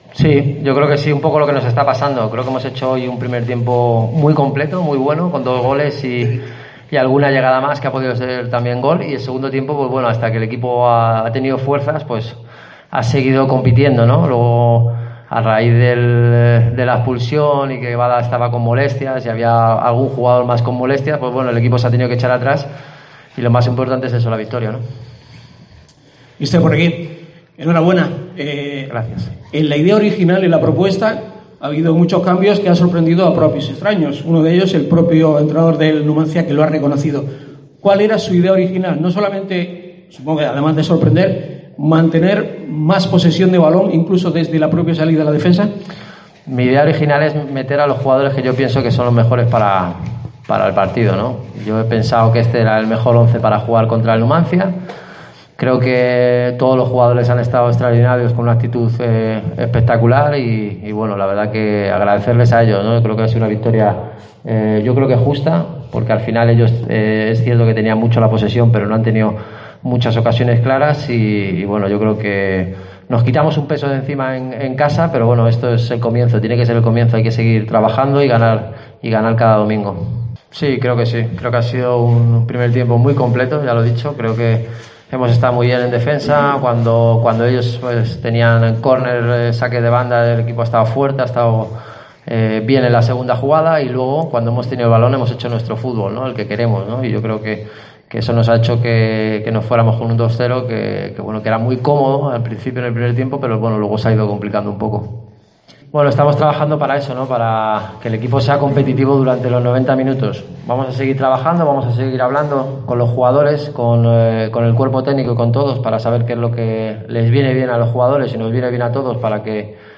Rueda de prensa de 'Guti' tras el 2-0 ante el Numancia.